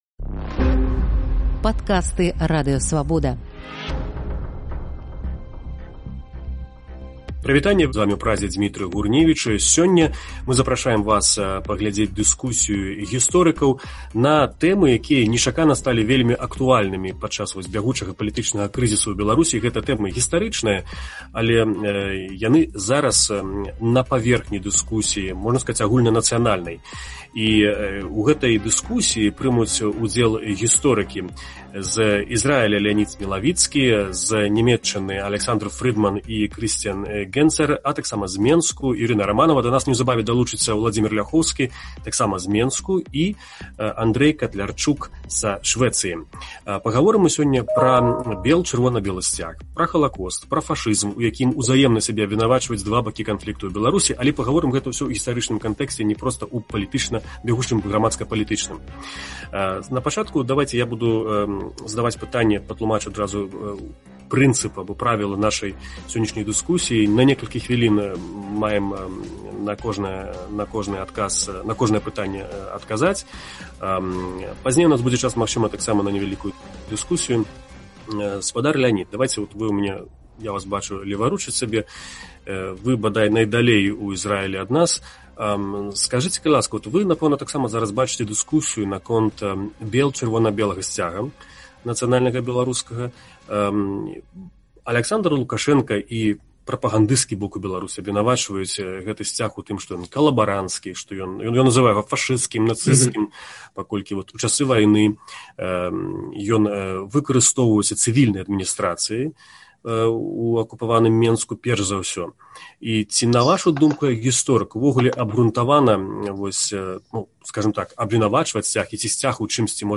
Гісторыкі зь Беларусі, Ізраілю і Нямеччыны пра афіцыйную прапаганду